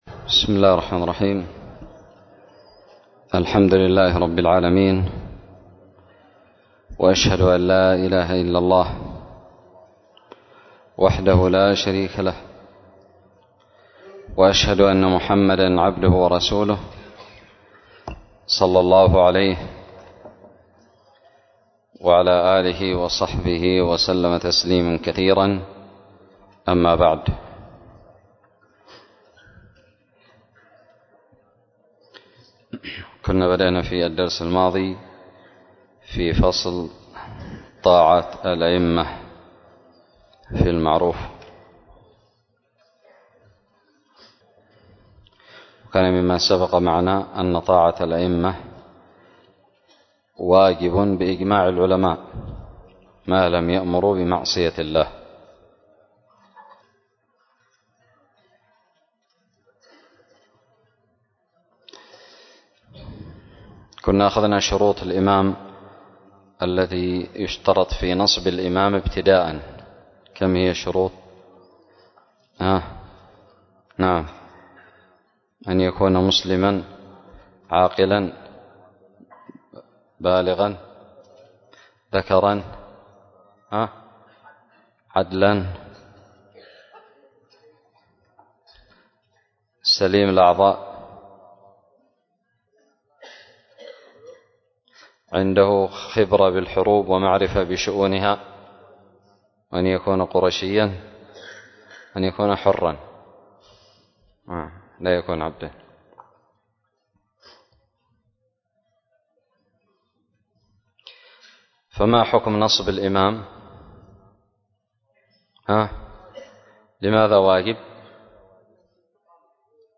الدرس السابع والخمسون من كتاب الجهاد من الدراري
ألقيت بدار الحديث السلفية للعلوم الشرعية بالضالع